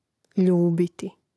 ljúbiti ljubiti